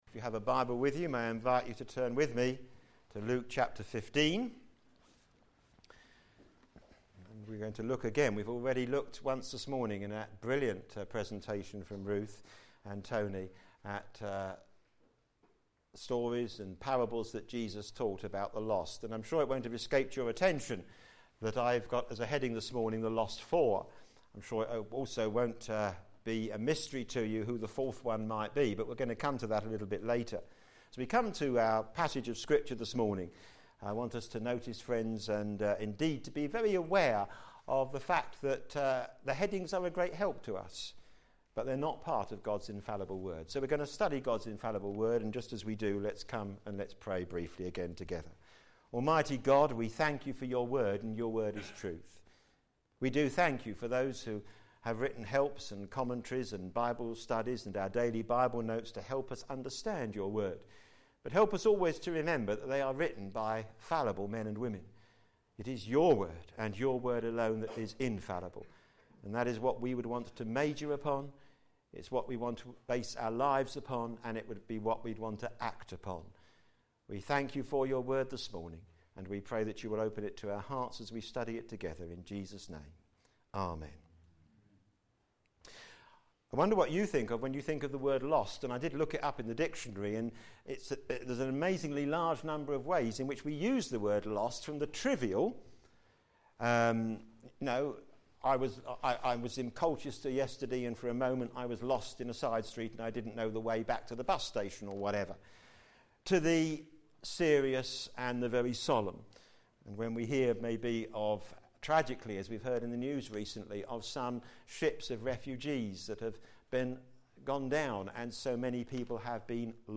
The Lost Four Sermon